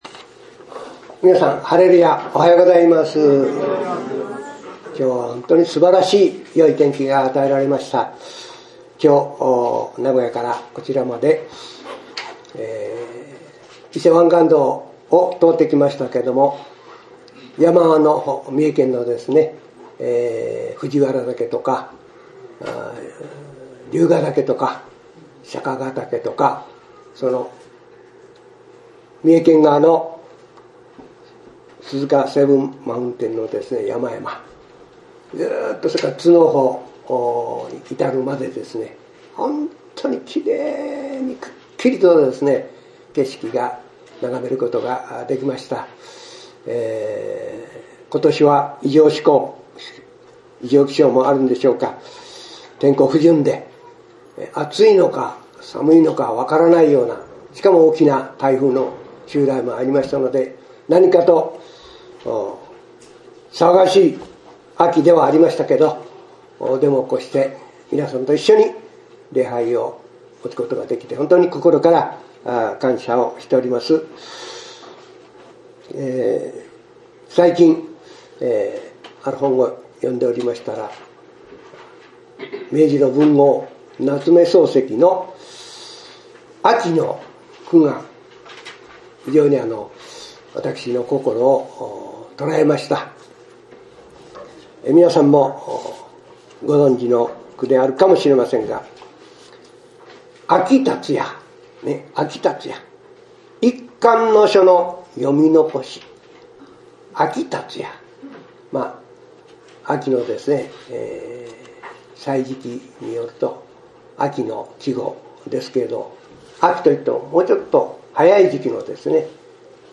聖書:マルコの福音書 11章1節～11節 メッセージ